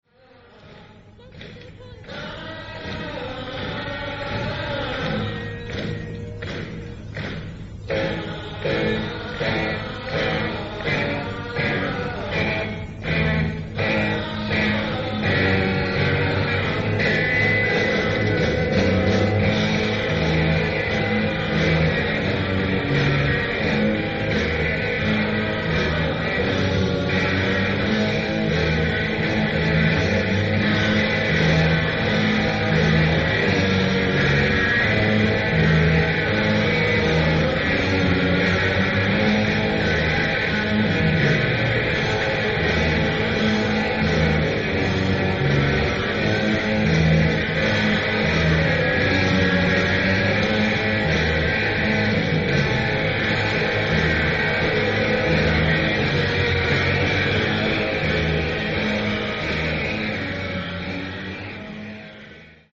Venue:  Radstadion
Sound:  Remastered
Source:  Audience Recording